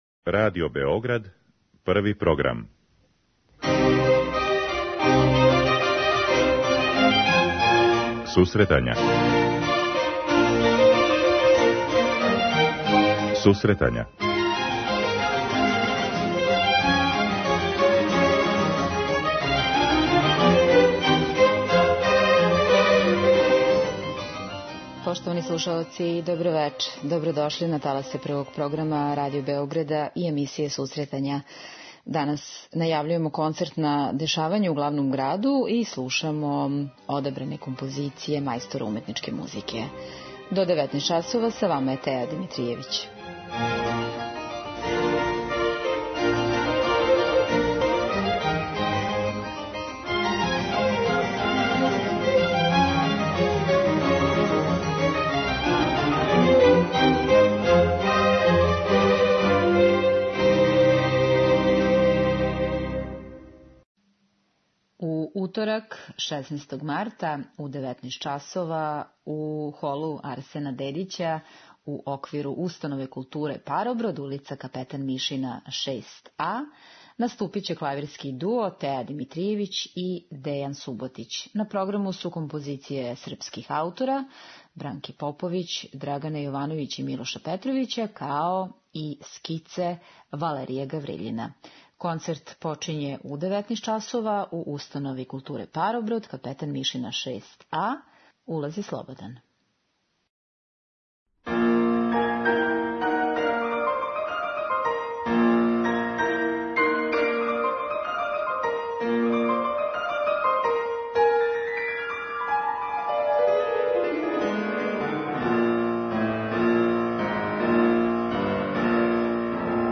У данашњој емисији најављујемо концертна дешавања у Установи културе 'Пароброд', Коларчевој задужбини и Београдској филхармонији и слушамо композиције Моцарта, Прокофјева, Рахмањинова и Дебисија.